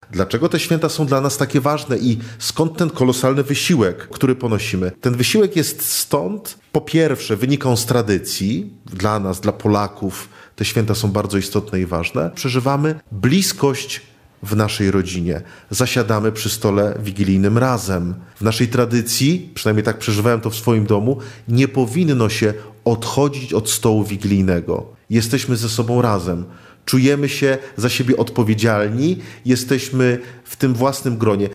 Bliskość i wspólnota. Teolog o przeżywaniu Bożego Narodzenia